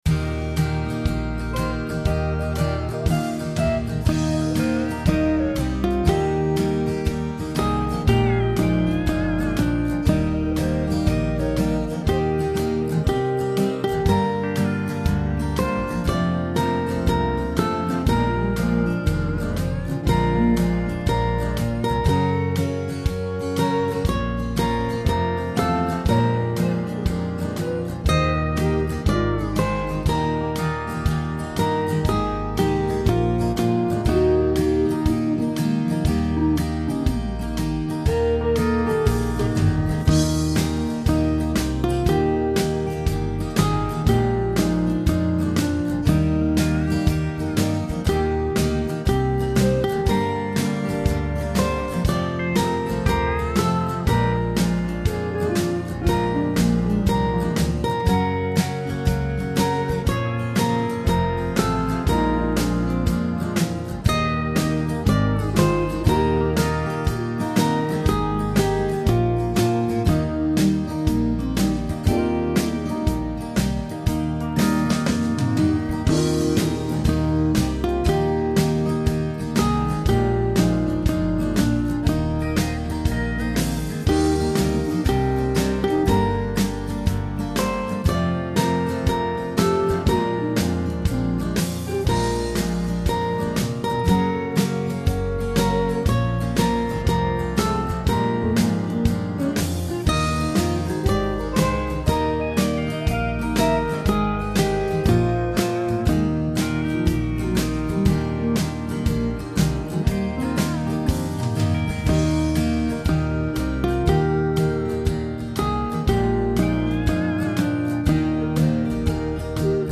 I had terrible trouble getting this one going so I apologise if the backings are less than stellar. I had several goes in several styles so take your pick of these three.